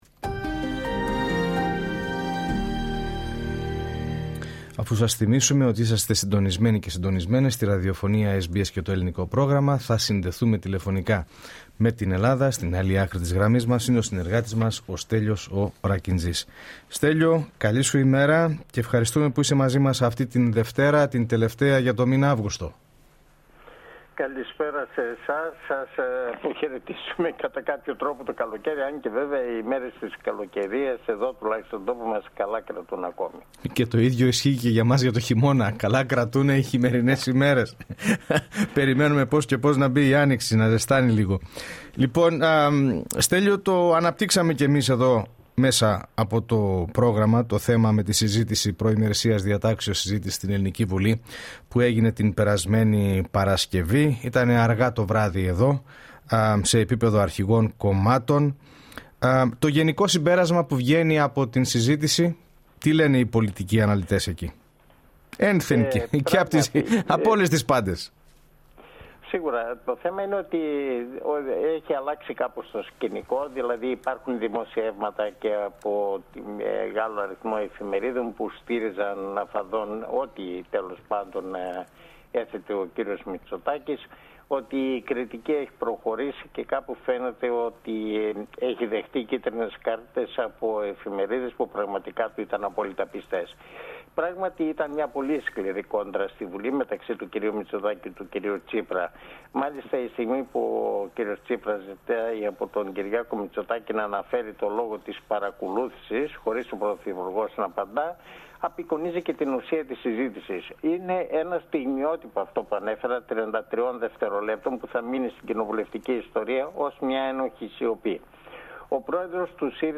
Για περισσότερα, ακούστε την ανταπόκρισή μας από την Ελλάδα, πατώντας το Play κάτω από τον τίτλο της είδησης.
ανταποκριση-απο-ελλαδα-29-αυγουστου.mp3